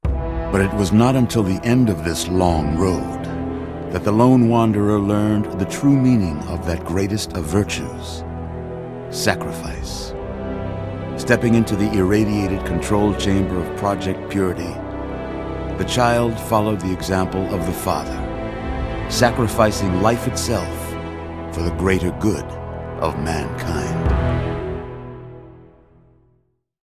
Category:Fallout 3 endgame narrations Du kannst diese Datei nicht überschreiben.